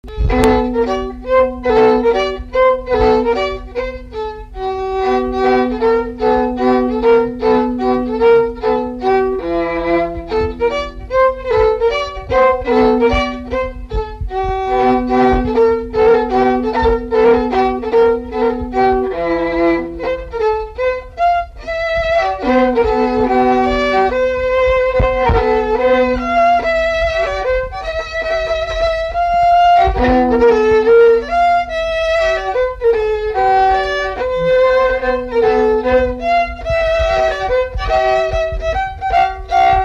Chants brefs - A danser
Résumé instrumental
danse : varsovienne
Pièce musicale inédite